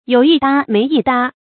有一搭沒一搭 注音： ㄧㄡˇ ㄧ ㄉㄚ ㄇㄟˊ ㄧ ㄉㄚ 讀音讀法： 意思解釋： 表示故意找話說。